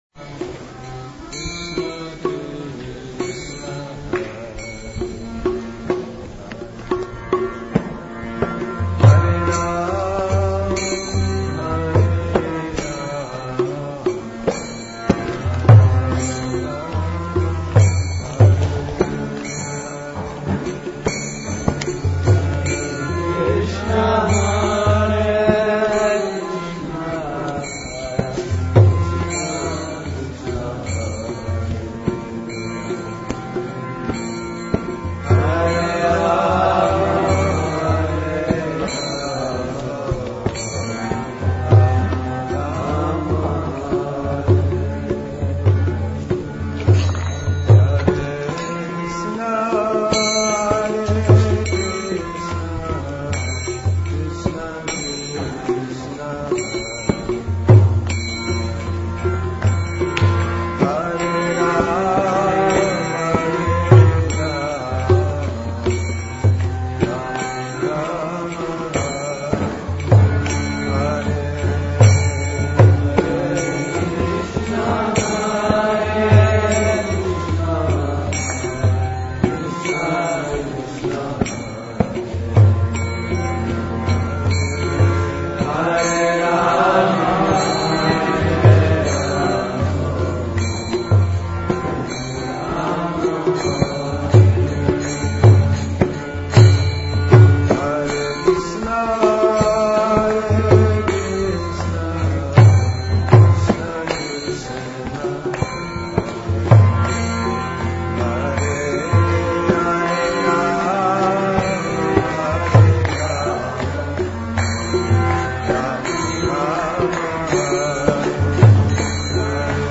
Kirtana